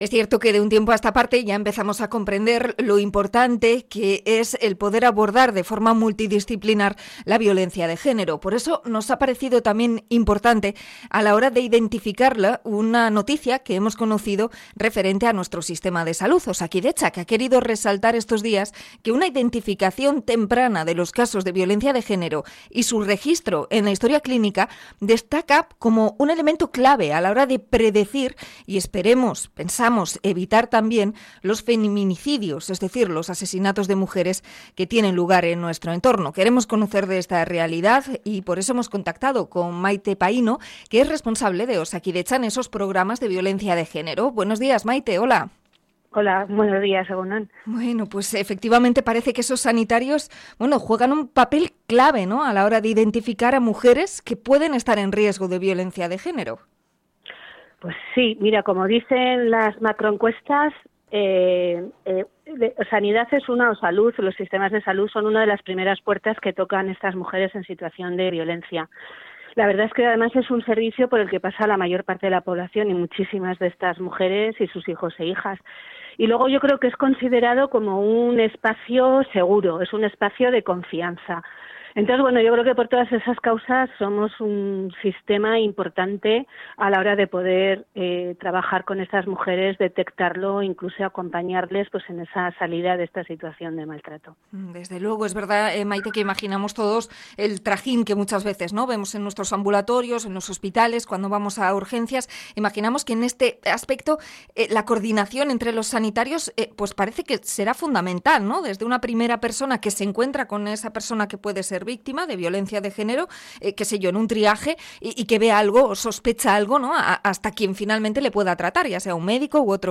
Entrevista a Osakidetza por estudio del riesgo de feminicidio